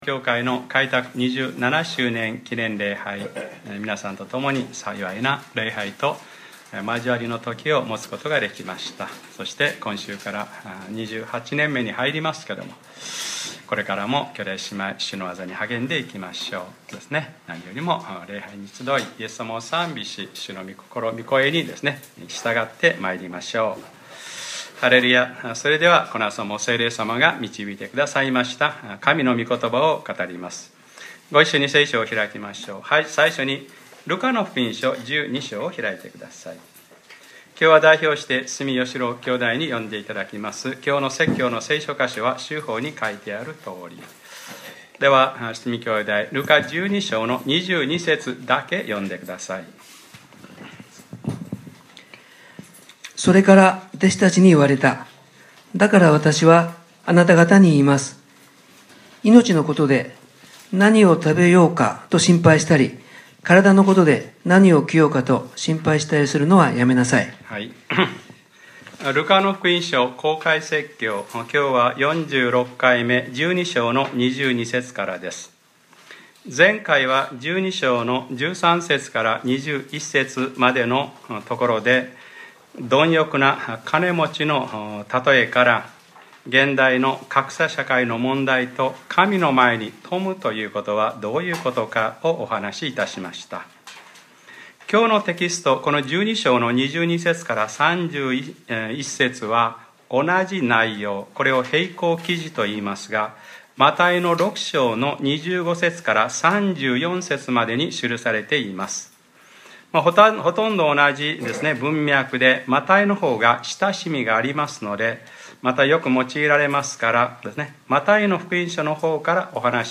2014年10月14日（日）礼拝説教 『ルカｰ４６：心配するのはやめなさい』